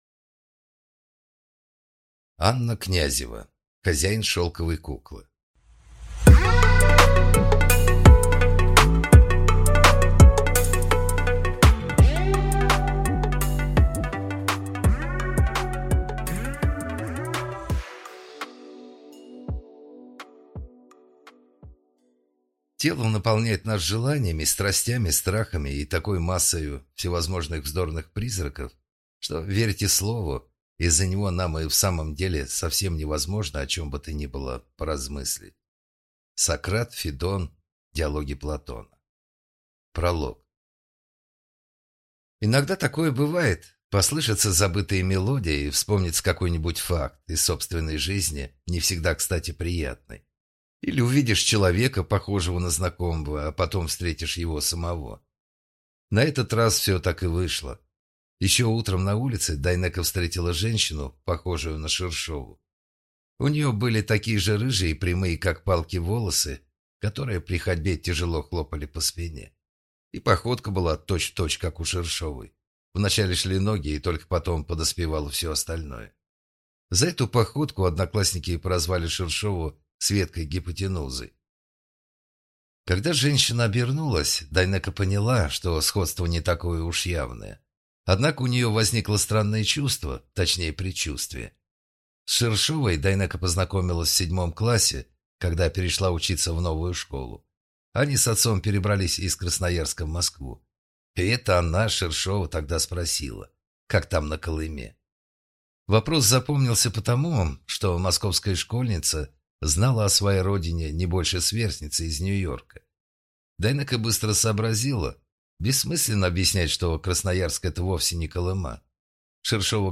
Аудиокнига Хозяин шелковой куклы | Библиотека аудиокниг